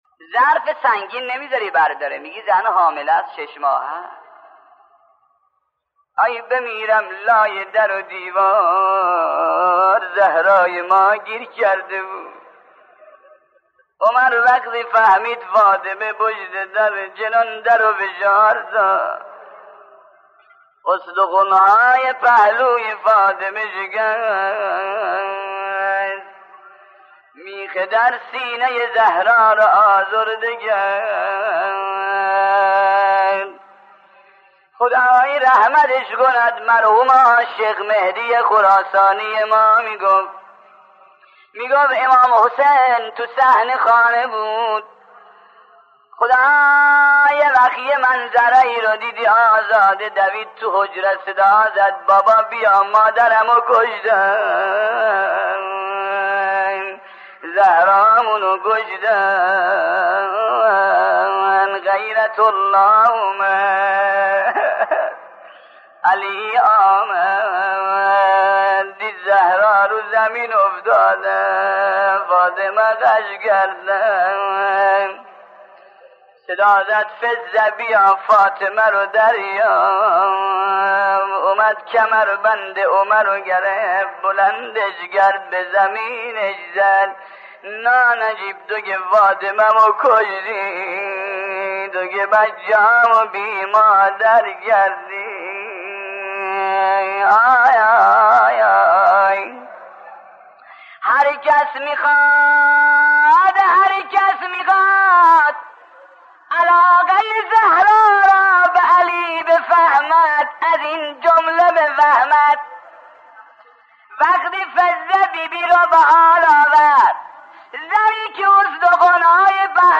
روضه حضرت زهرا (س) به روایت شهید حاج شيخ احمد ضيافتی كافی